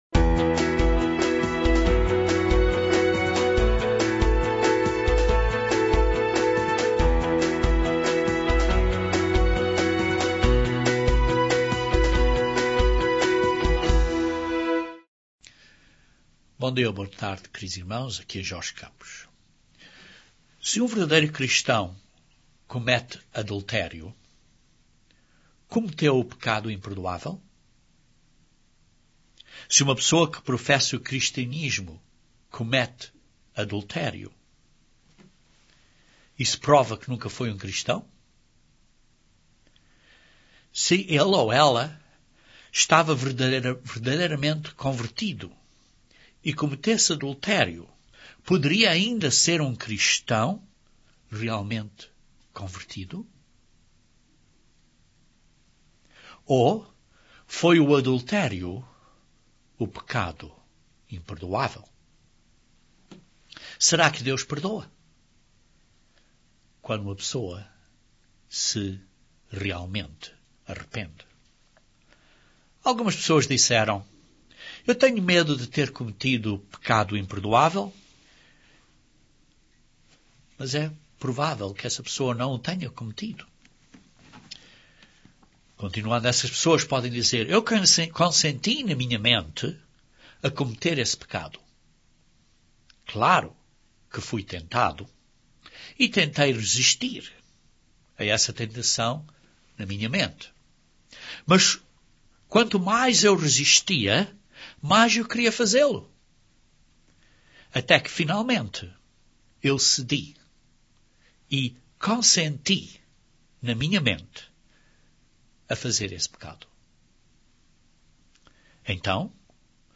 Este sermão aborda estes assuntos e dá respostas da Bíblia a estas questões.